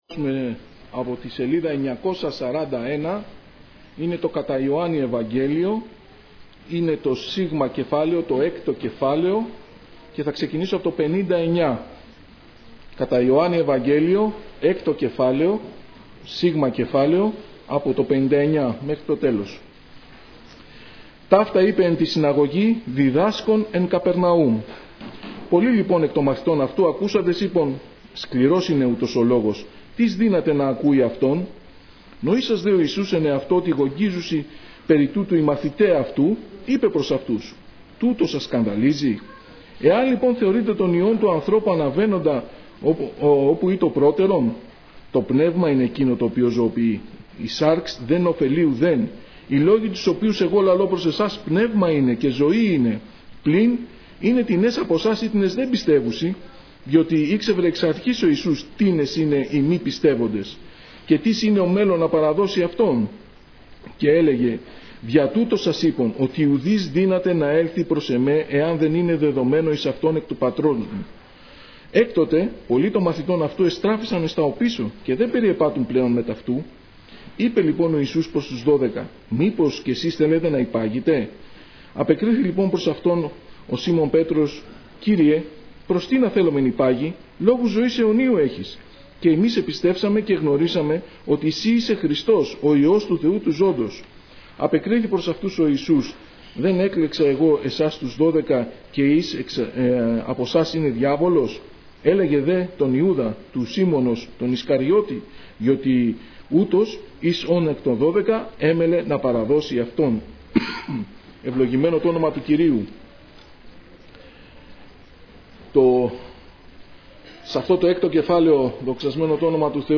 Κηρύγματα